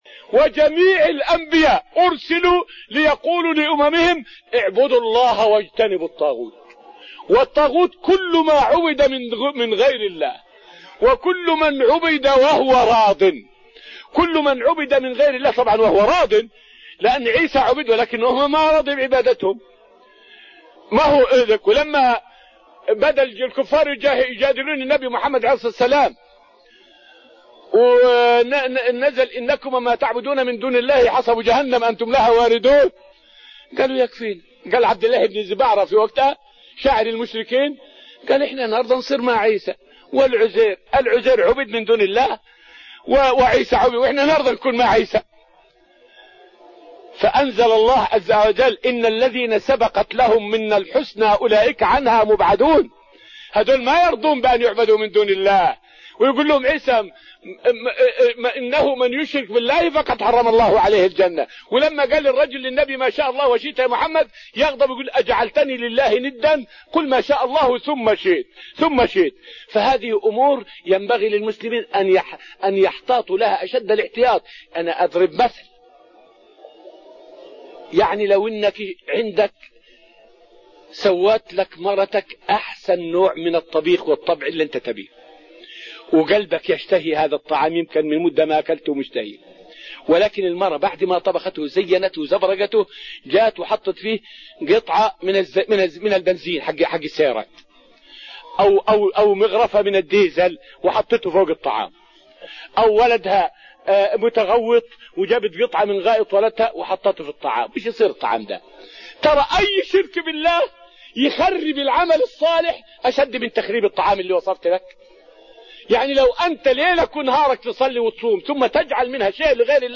فائدة من الدرس التاسع من دروس تفسير سورة الذاريات والتي ألقيت في المسجد النبوي الشريف حول حكم الردة والفرق بين الشرك الأكبر والشرك الأصغر.